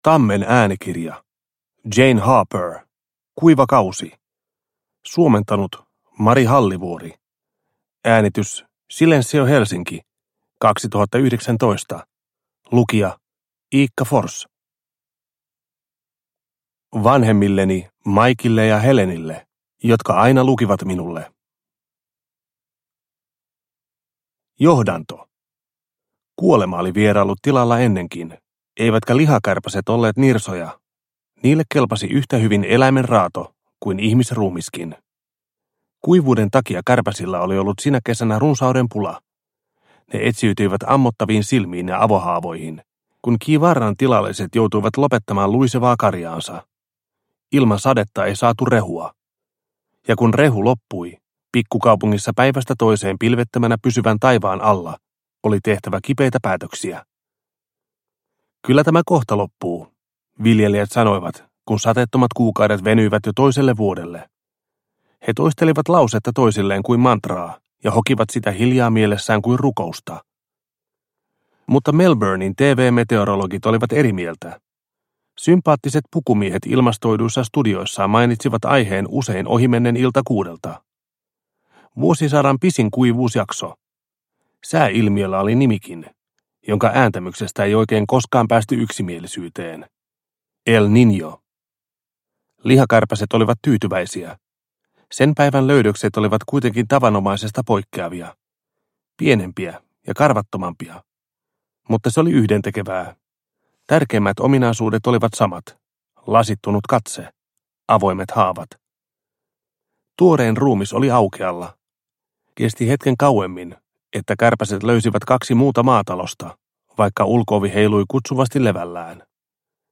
Kuiva kausi – Ljudbok – Laddas ner